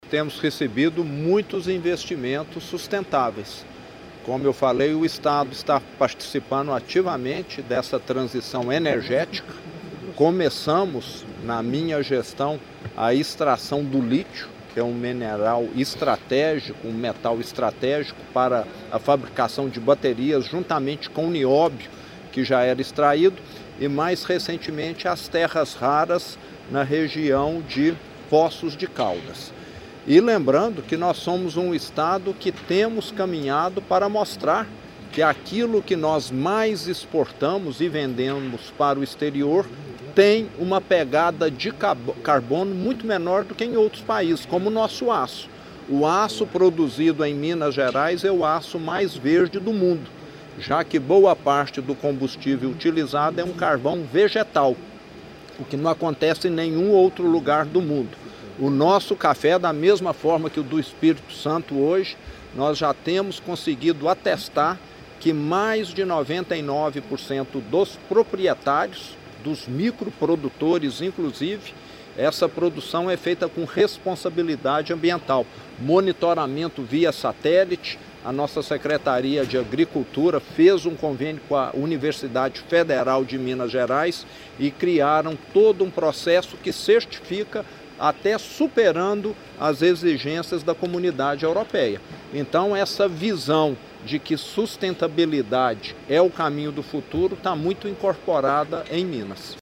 Sonora do governador de Minas Gerais, Romeu Zema, sobre a 13ª edição do Cosud